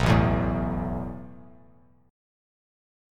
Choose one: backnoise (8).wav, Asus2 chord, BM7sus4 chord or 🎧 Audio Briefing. Asus2 chord